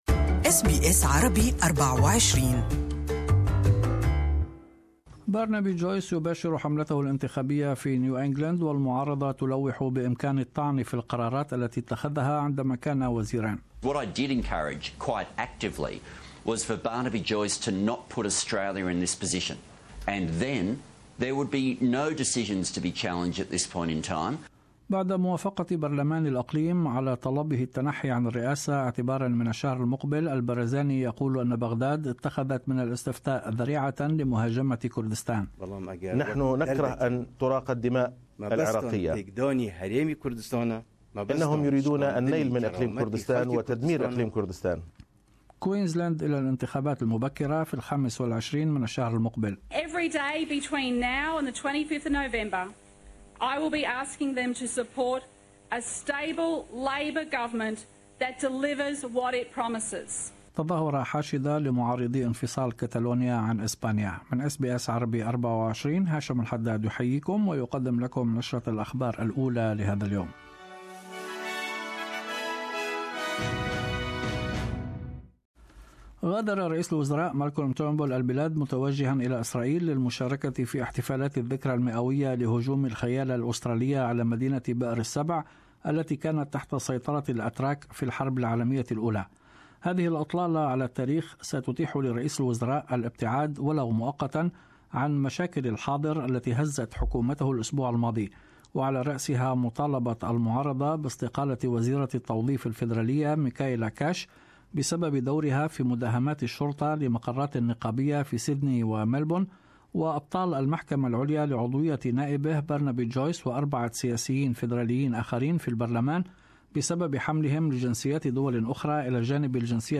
this bulletin ...